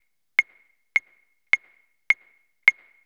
Clave.wav